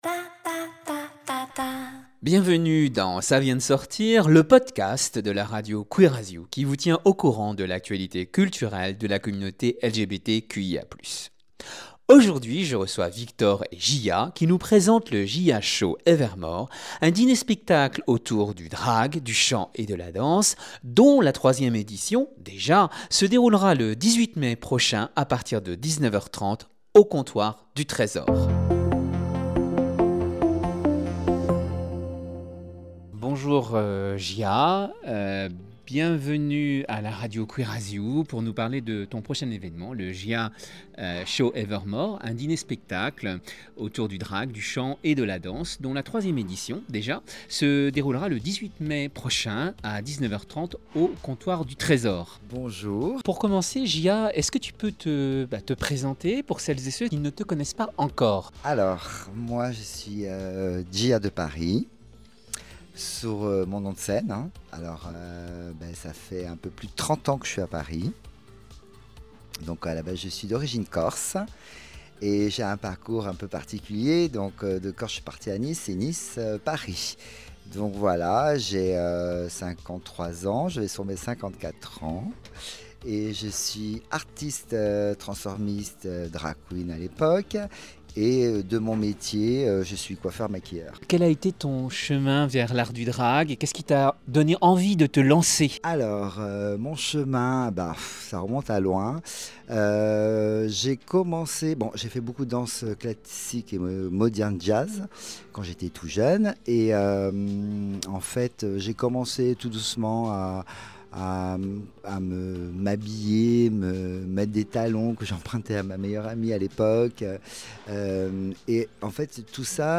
Bienvenue à LA Radio Queer as You, dans le podcast Ça vient de sortir !, ton agenda culturel dédié à la communauté LGBTQIA+.